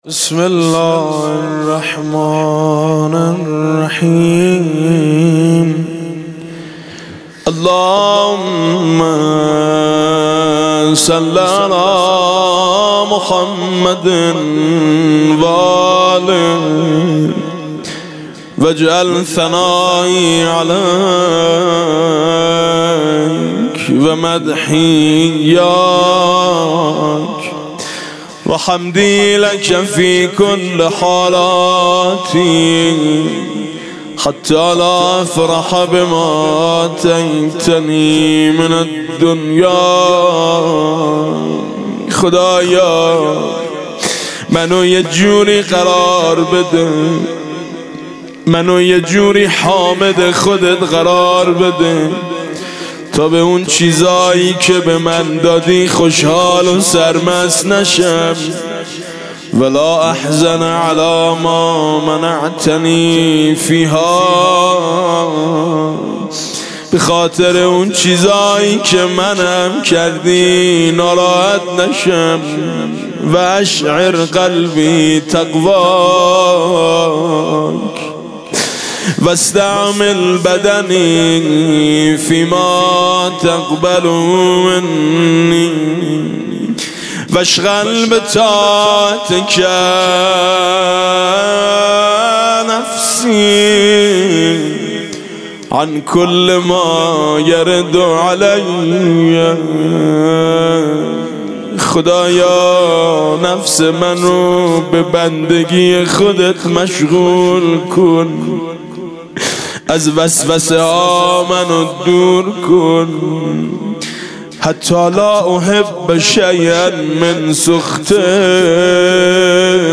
مناسبت : شب دهم رمضان - وفات حضرت خدیجه
مداح : میثم مطیعی قالب : مناجات